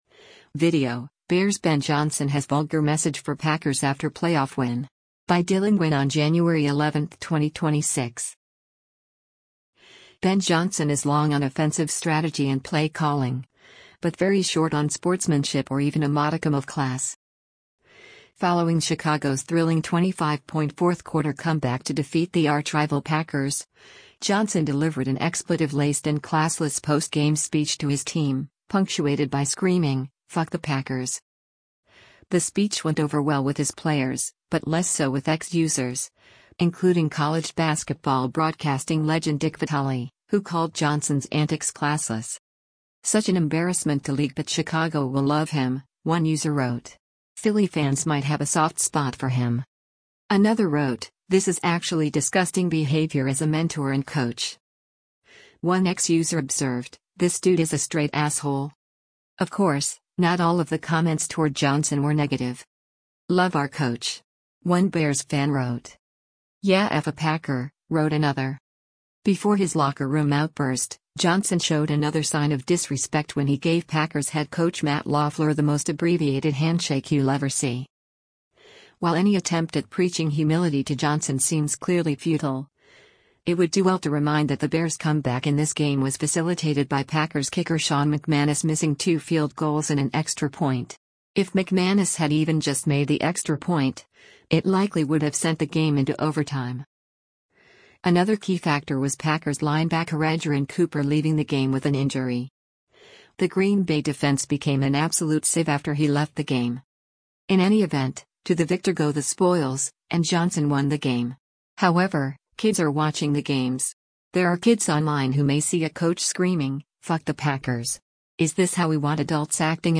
Following Chicago’s thrilling 25-point fourth-quarter comeback to defeat the archrival Packers, Johnson delivered an expletive-laced and classless post-game speech to his team, punctuated by screaming, “F*ck the Packers!”